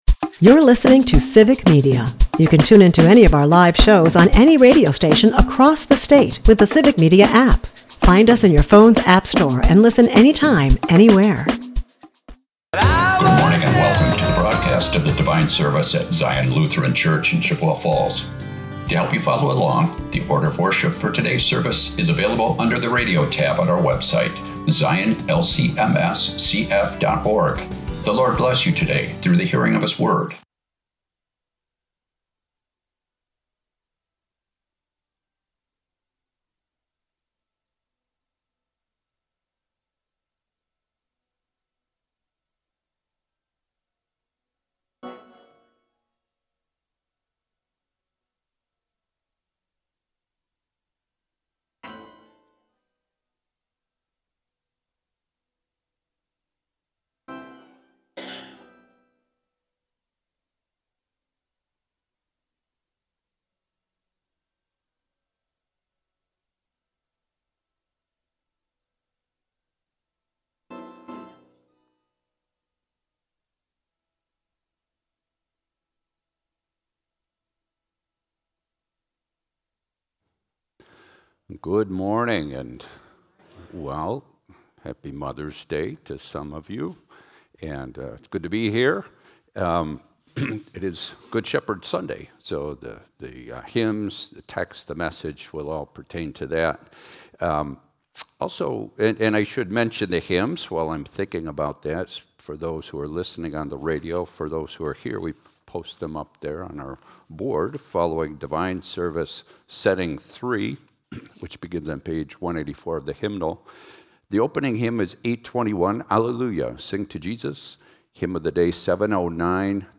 Sunday Service - Civic Media